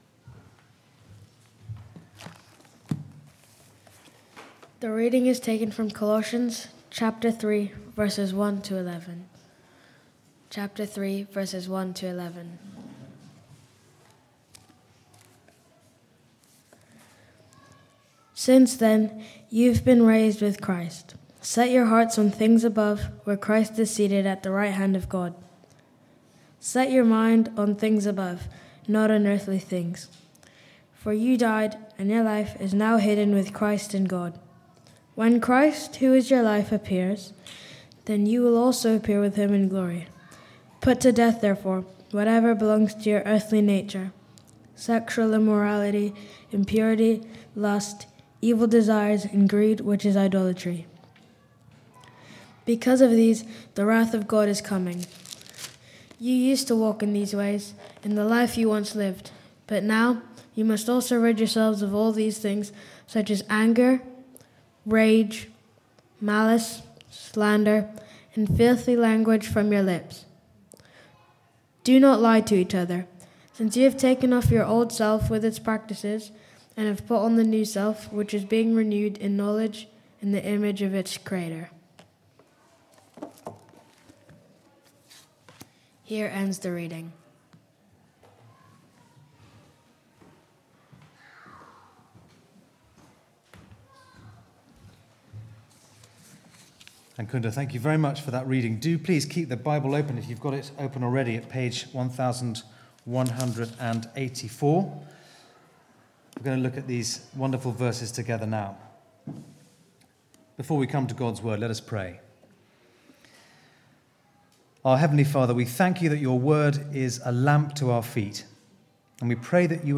Passage: Colossians 3:1-11 Service Type: Weekly Service at 4pm « No Disqualification in Christ Hidden with Christ
24th-Oct-Sermon.mp3